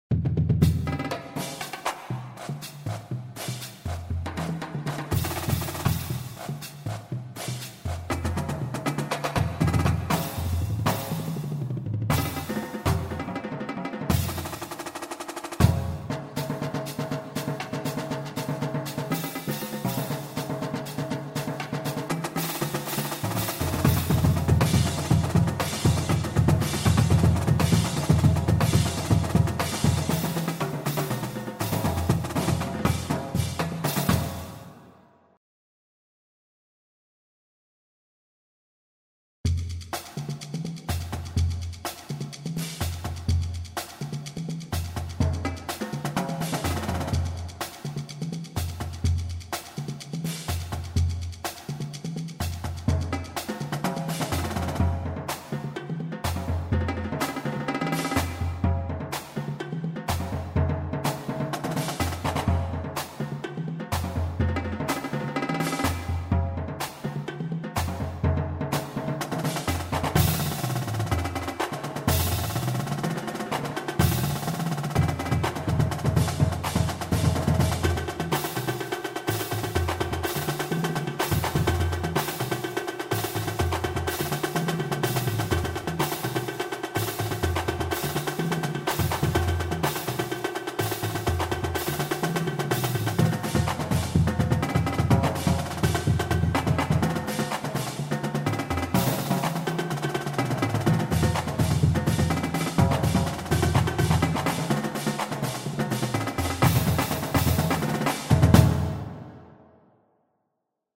Genre: Drumline
Snare Drums
Quints
Bass Drums
Cymbals